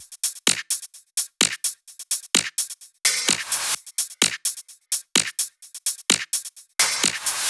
VFH2 128BPM Capone Kit 6.wav